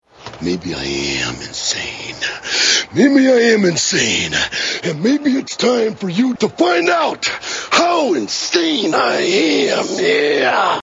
insane macho man Meme Sound Effect
This sound is perfect for adding humor, surprise, or dramatic timing to your content.